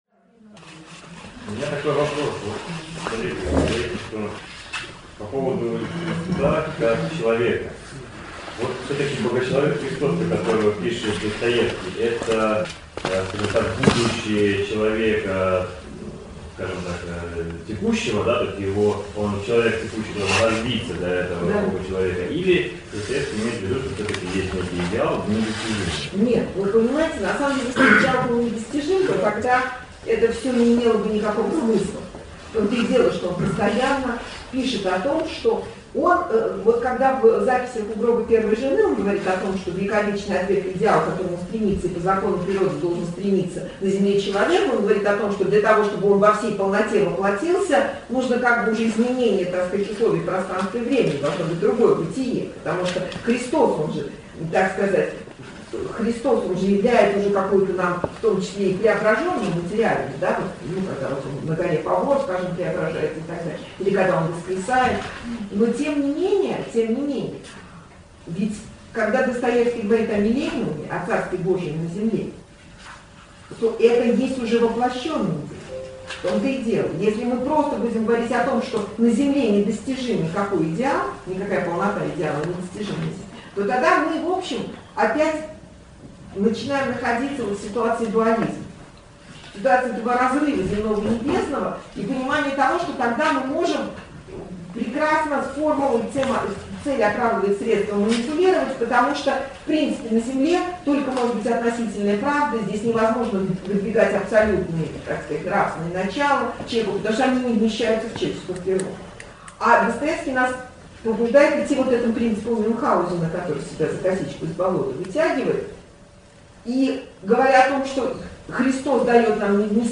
Во второй лекции, прочитанной 16 октября